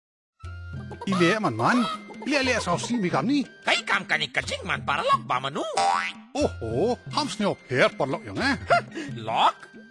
Report is third in the series of five Radio PSA and address backyard farmers and their families. It uses a performer and a rooster puppet as a creative medium to alert families to poultry diseases and instill safe poultry behaviours.
Radio PSA